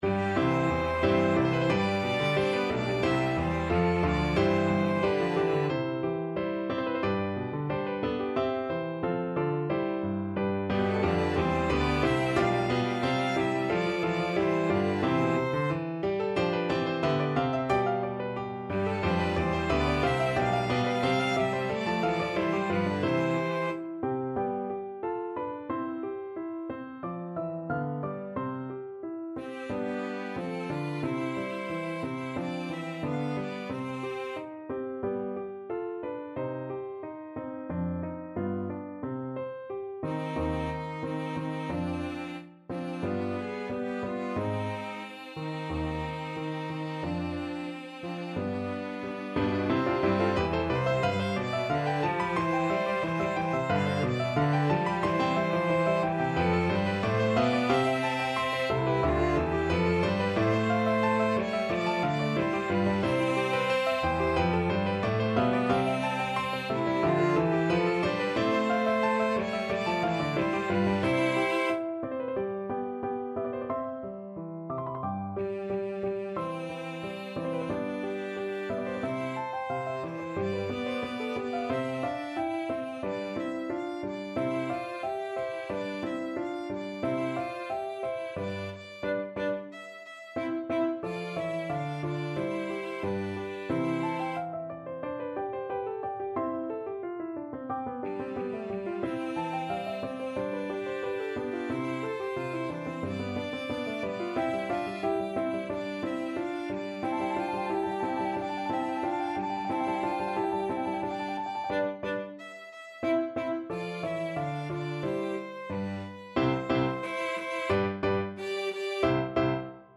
ViolinCelloPiano
Allegro =180 (View more music marked Allegro)
4/4 (View more 4/4 Music)
Classical (View more Classical Piano Trio Music)